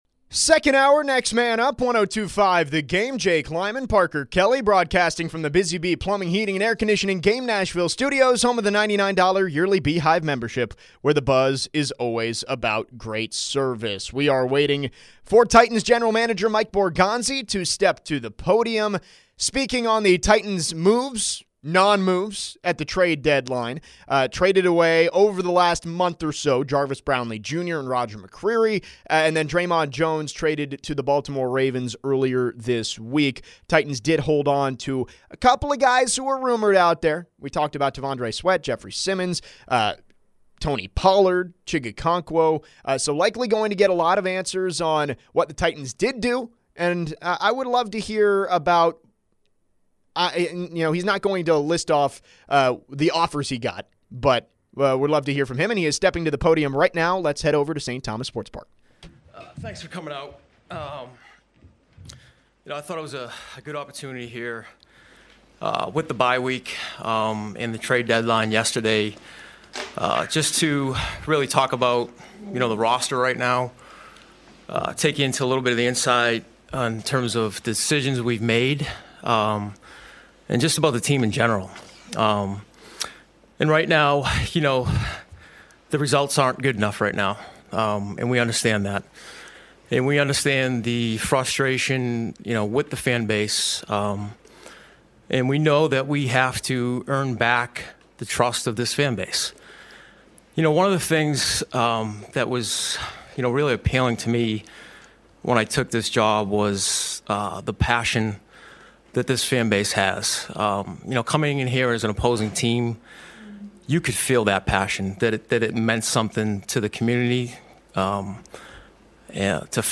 Titans General Manager Mike Borgonzi speaks to the media from Ascension St. Thomas Sports Park.